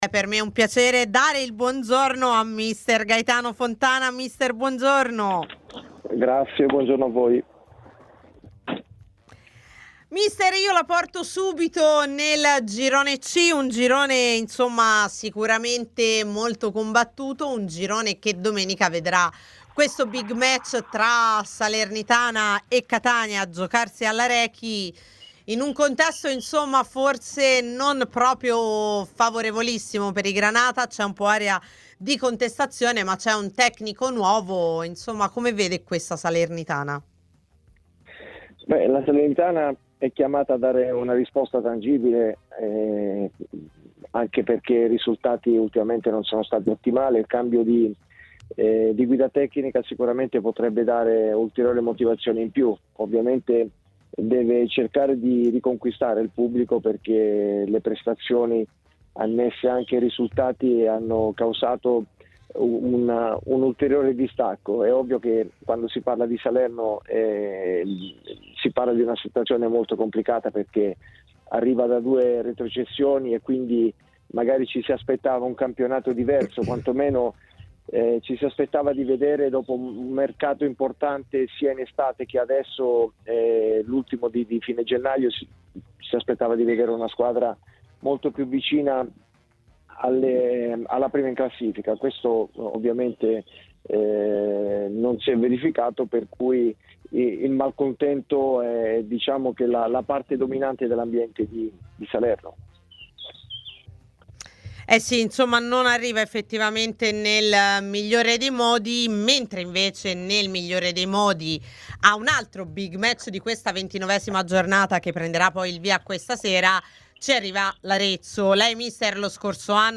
Mister, partiamo dal Girone C: big match tra Salernitana e Catania.